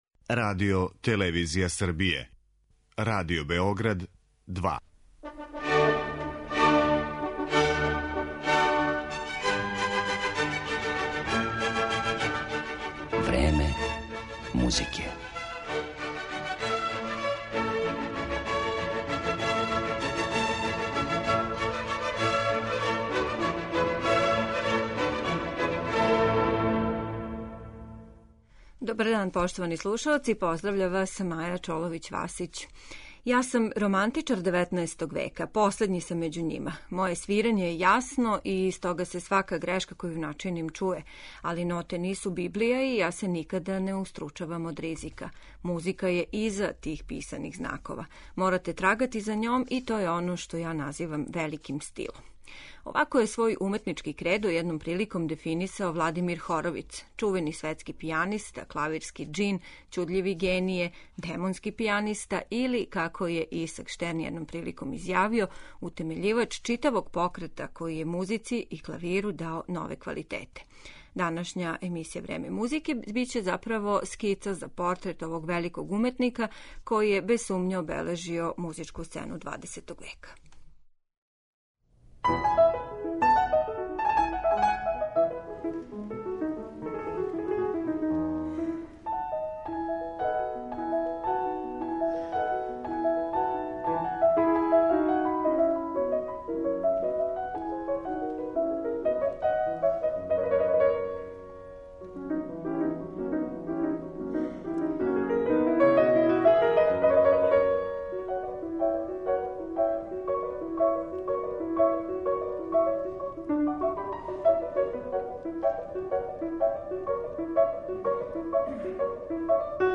У данашњој емисији ћете слушати дела Скарлатија, Шопена, Листа и Рахмањинова.